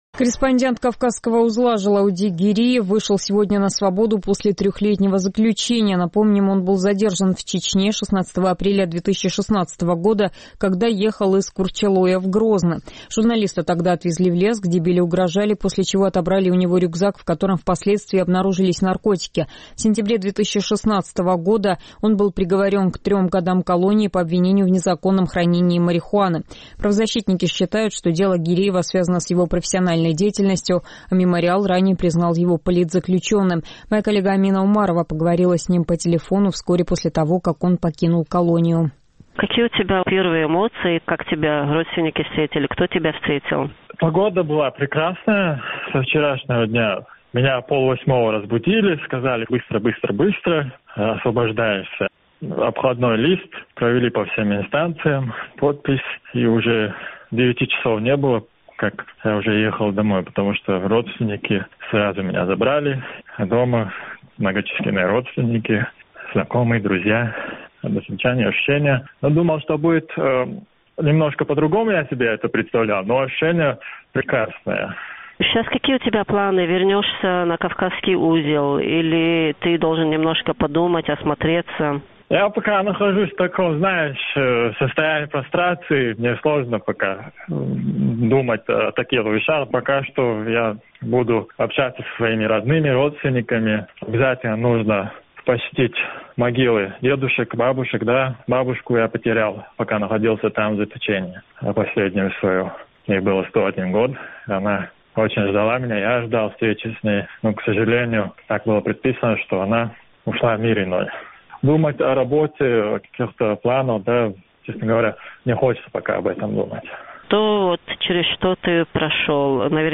Мы поговорили с ним по телефону вскоре после того, как он покинул колонию.